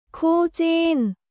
クー・ジン